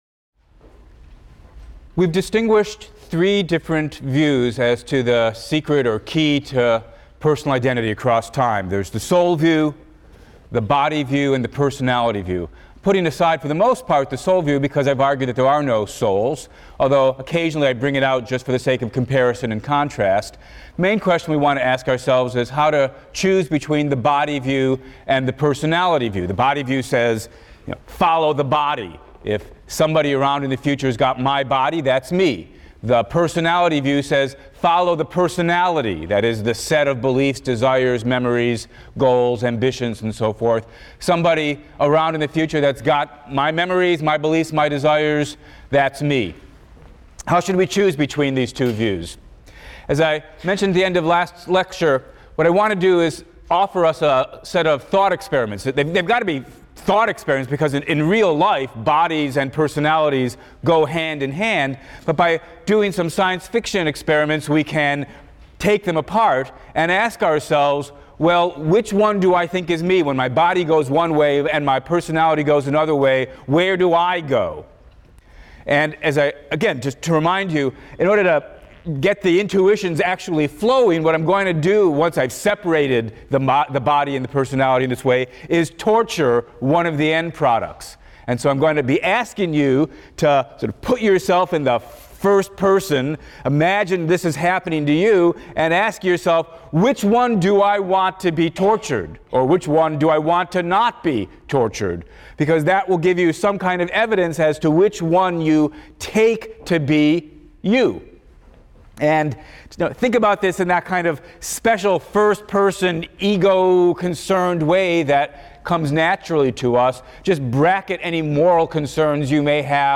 PHIL 176 - Lecture 12 - Personal Identity, Part III: Objections to the Personality Theory | Open Yale Courses